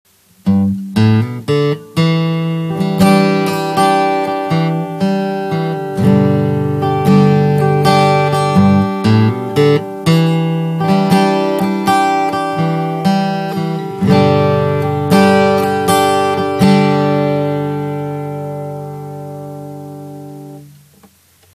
Les enregistrements à partir du micro intégré sont concentrés dans les médium et les haut-médiums.
Un exemple sonore, enregistré avec iRig Recorder :
Micro Intégré :
Test micro iPhone intégré